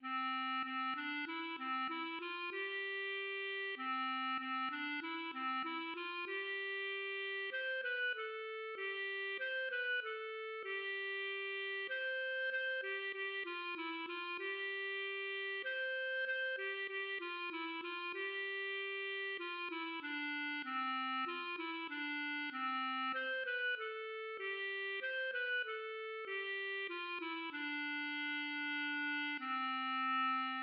German hymn tune
Meter88.88 with Alleluias
The verse consists of two repeated musical phrases with matching rhythms ("V", "v"), one using the upper pitches of the major scale and one using the lower pitches, and likewise for the Alleluia refrain ("R", "r").